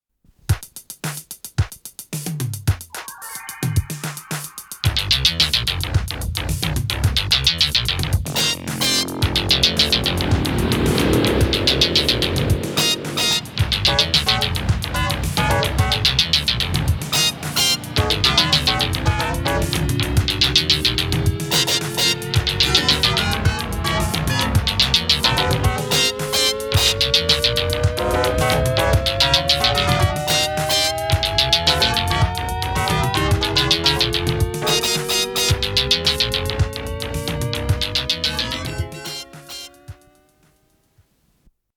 с профессиональной магнитной ленты
ПодзаголовокОригинал - для фортепиано, до минор
Скорость ленты38 см/с